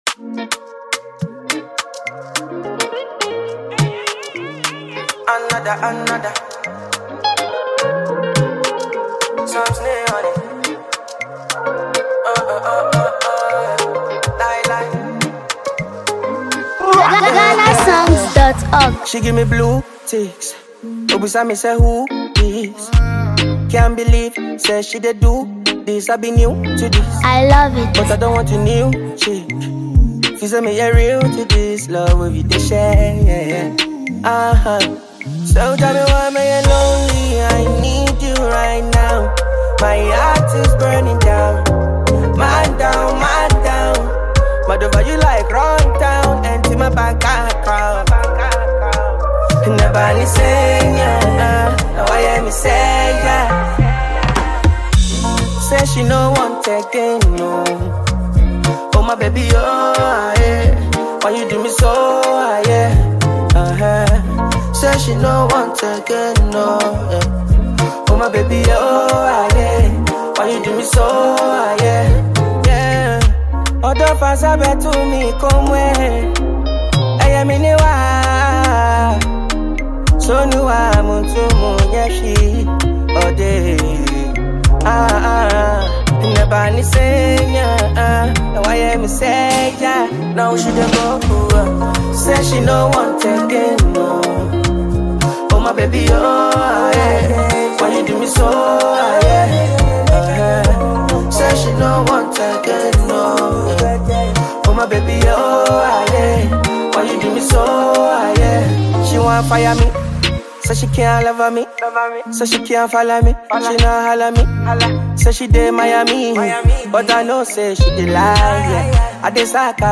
smooth vocals
and refreshing Afro-fusion rhythm
melodic storytelling
deeply emotional Afrobeat record